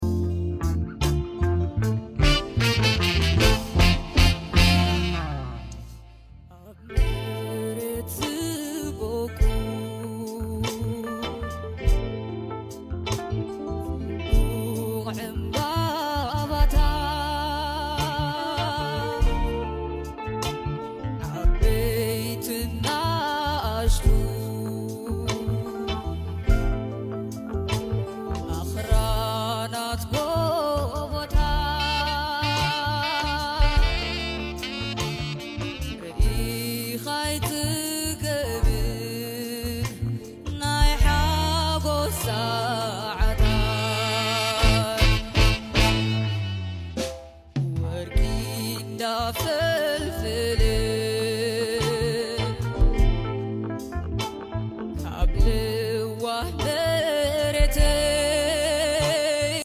This wasn’t just a concert— It was a cultural revival.